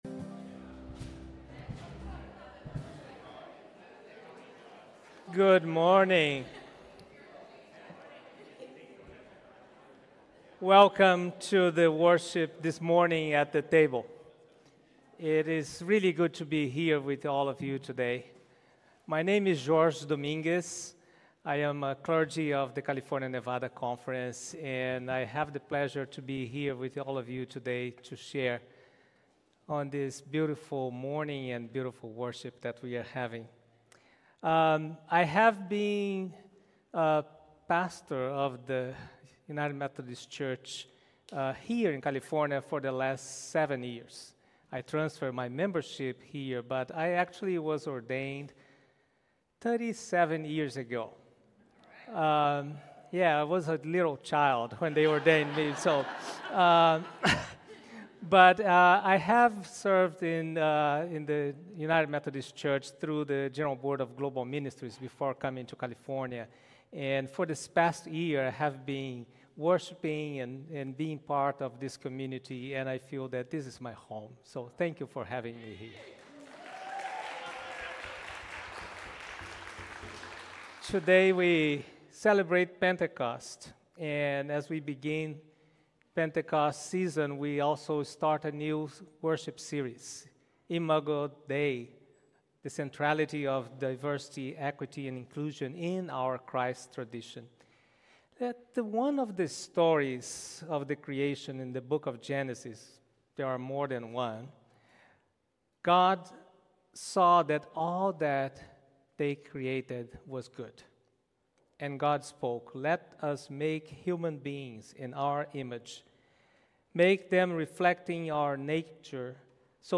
rooted in grace Imago DEI Acts Watch Listen Save This is the first in a four-week worship series on the centrality of DEI in our Christ tradition.